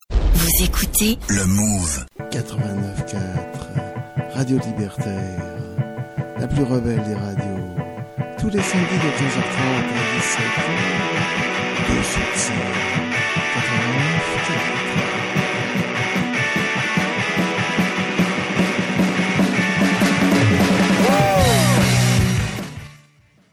Improvisation musicale sur Radio Libertaire, émission « Deux sous de scène »
Lecture poème accompagnée d’une improvisation à la flûte traversière